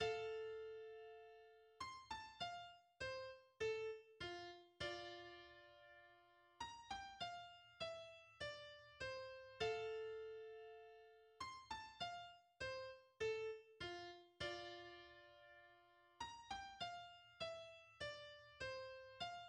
Symphonie «no 43» en fa majeur
Genre Symphonie
premiers violons, seconds violons,
altos, violoncelles, contrebasses
2 hautbois,
2 bassons
2 cors
Introduction de l'Allegro maestoso :